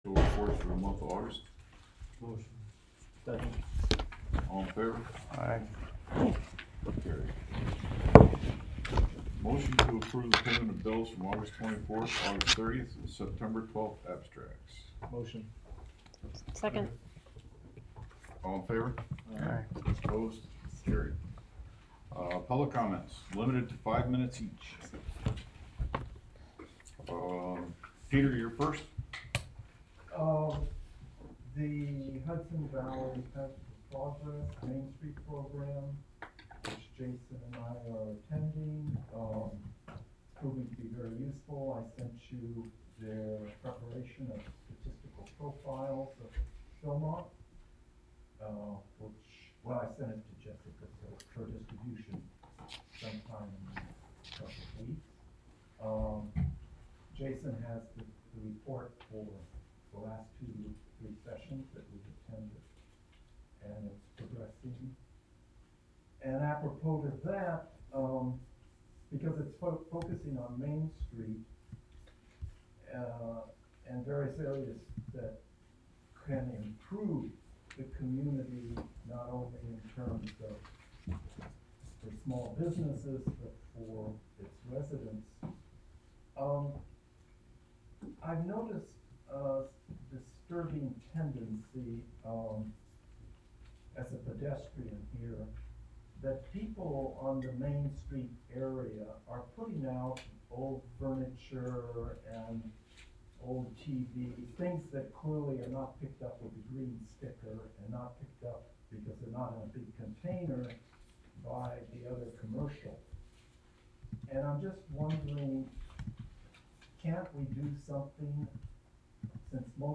Live from the Village of Philmont: Village Board Meeting (Audio)
Live from the Village of Philmont: Village Board Meeting (Audio) Sep 12, 2022 shows Live from the Village of Philmont Live stream of the Village of Philmont public meetings.